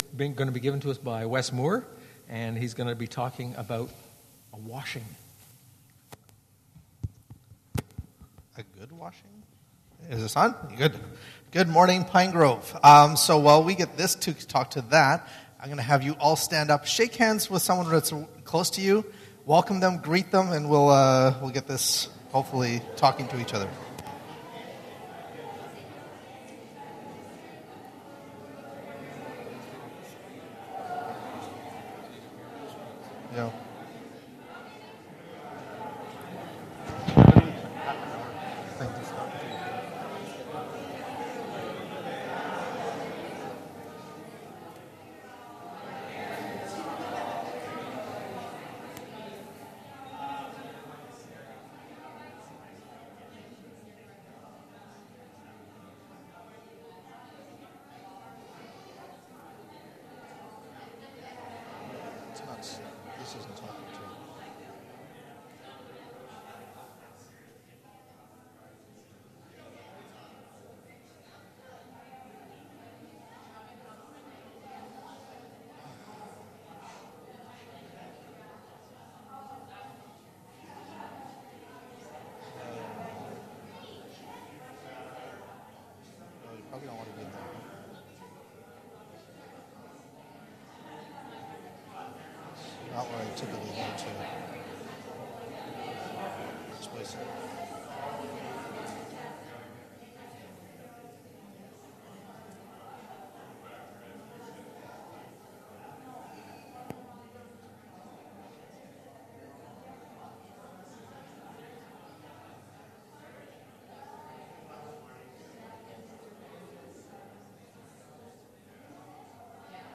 PG Sermons